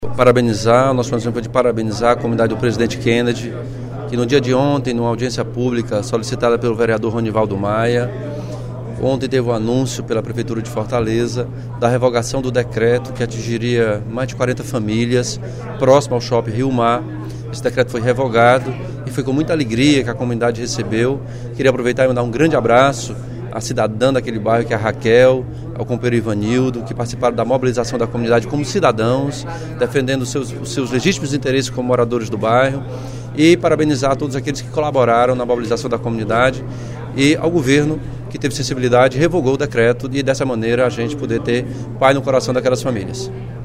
O deputado Elmano Freitas (PT) parabenizou, no primeiro expediente da sessão plenária desta quinta-feira (10/03), os moradores do bairro Presidente Kennedy pela revogação do decreto de desocupação de áreas para construção de um shopping.